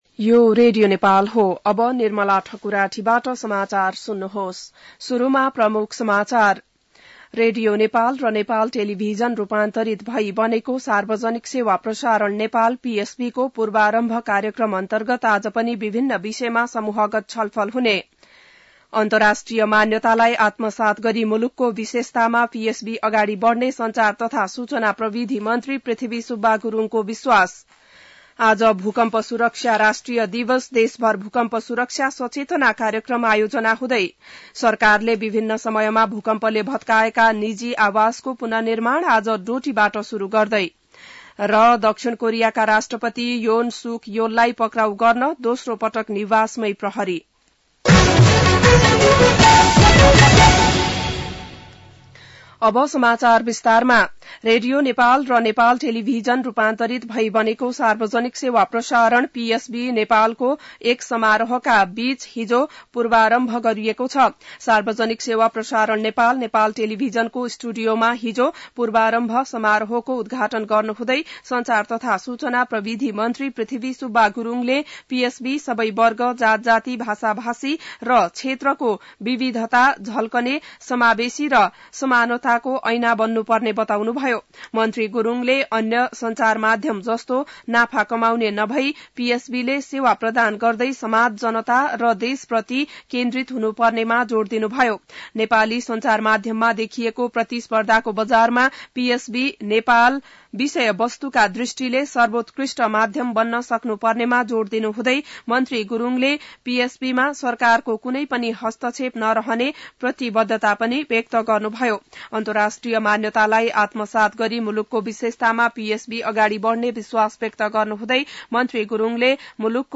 An online outlet of Nepal's national radio broadcaster
बिहान ९ बजेको नेपाली समाचार : ३ माघ , २०८१